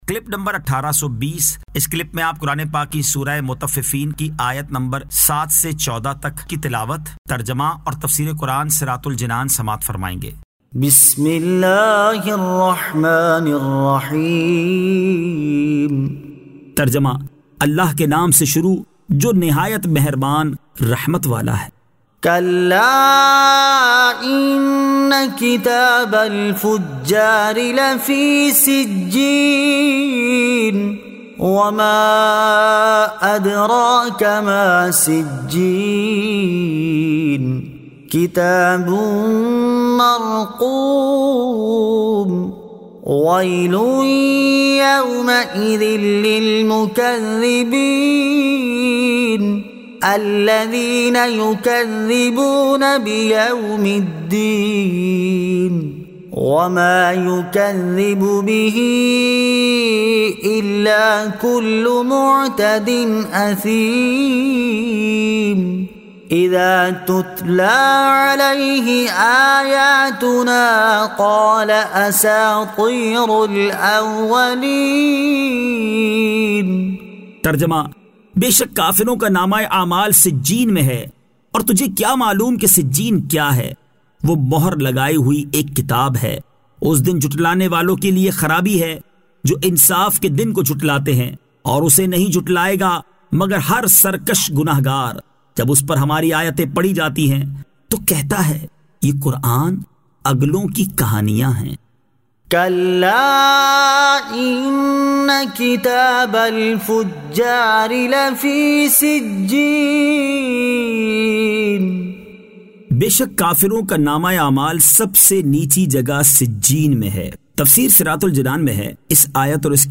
Surah Al-Mutaffifeen 07 To 14 Tilawat , Tarjama , Tafseer